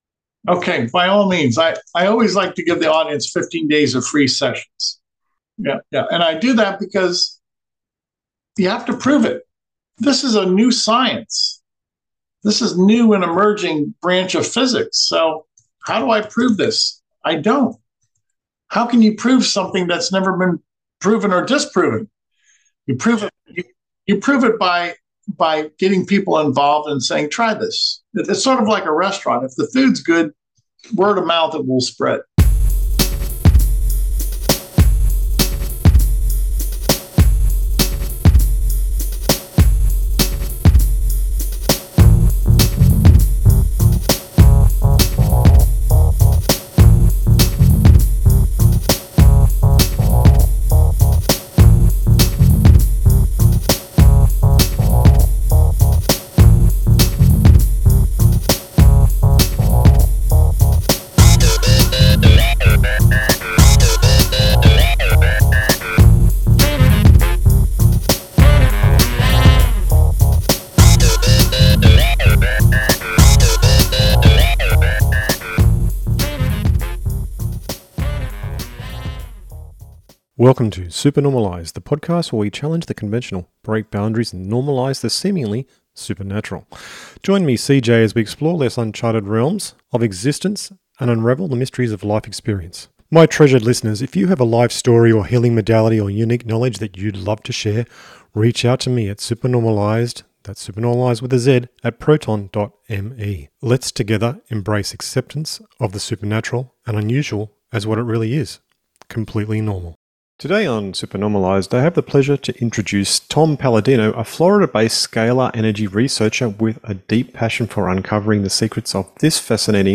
Interview What Is Scalar Energy Healing?